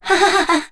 Kara-Vox-Laugh.wav